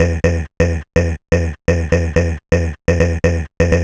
cch_vocal_art_125.wav